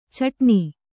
♪ caṭṇi